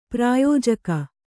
♪ prāyōjaka